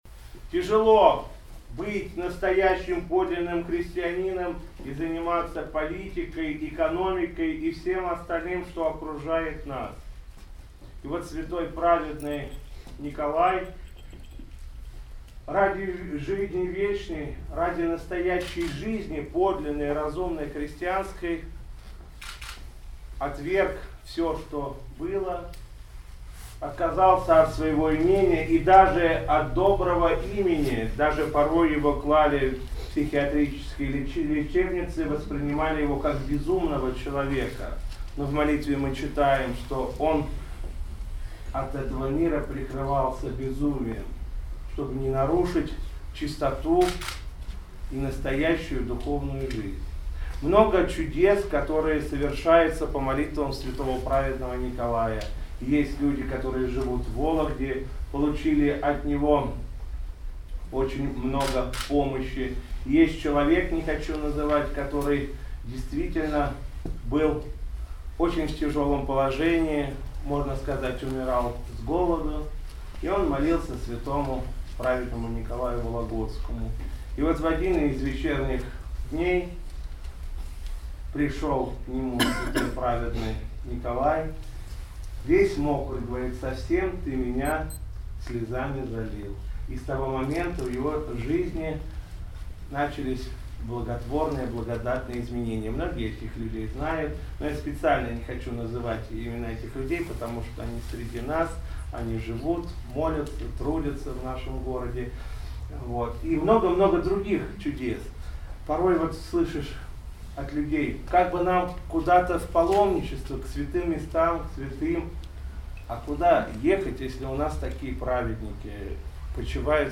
По окончании Литургии верующие, во главе с митрополитом Вологодским и Кирилловским Игнатием, прошли крестным ходом к часовне праведного Николая Вологодского, в которой владыка Игнатий совершил молебен и поклонился почитаемому вологодскому святому.
Правящий архиерей обратился к присутствующим с проповедью:
Проповедь в день памяти праведного Николая Вологодского